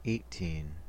Ääntäminen
UK : IPA : /ˈeɪ.tiːn/